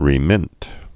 (rē-mĭnt)